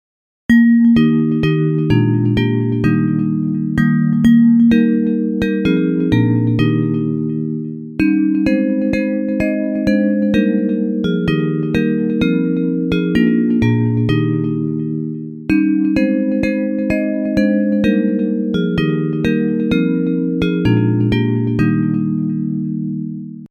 Bells Version
Music by: German folk tune